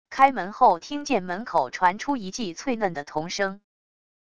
开门后听见门口传出一记脆嫩的童声wav音频